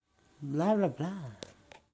静态 1010 k Hz 卫星风
描述：从数字调谐器上录下的收音机上午频段1010的静电。这种杂乱无章的噪音听起来就像一阵刺耳的风和一个老式的喋喋不休的卫星在鸣叫。
Tag: 无线电站 静态的 振幅调制 收音机 幅度调制 噪声